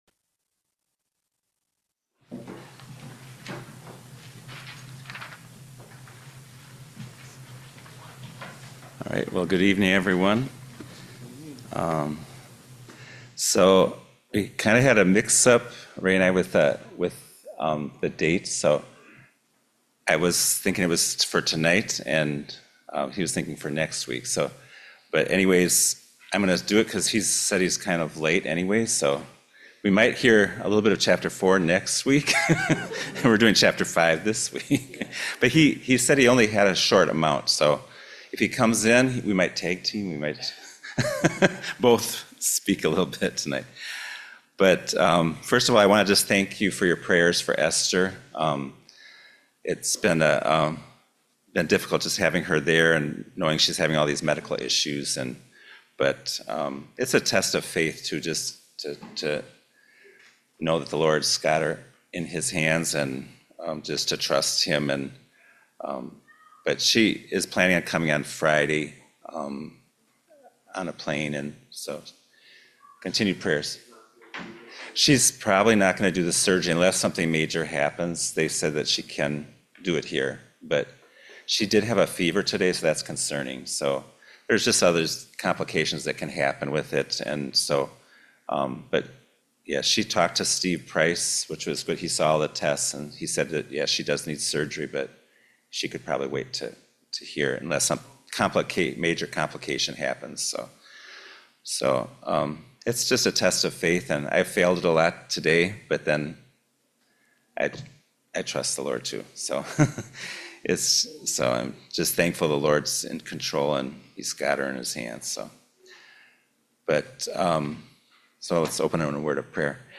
The Wauwatosa Bible Chapel recorded sermons in audio, video, and transcript formats